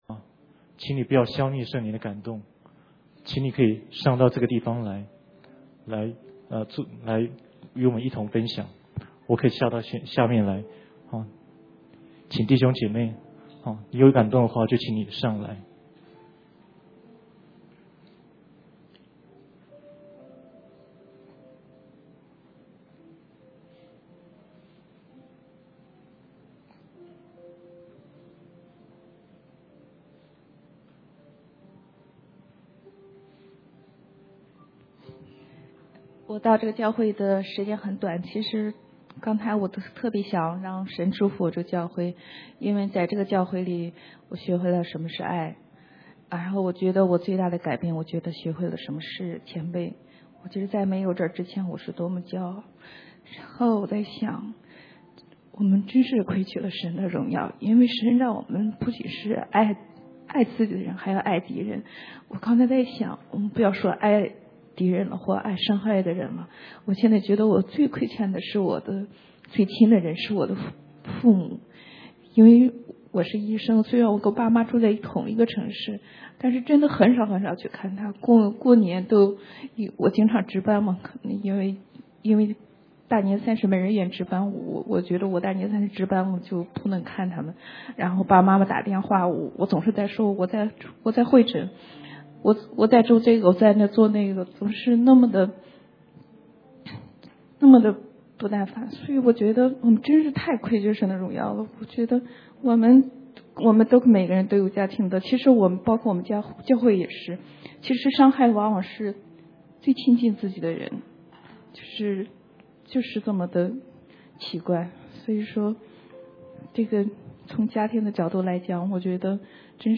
中文講道